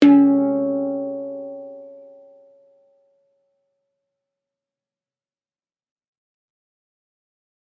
预览是没有双耳节拍的。
声道立体声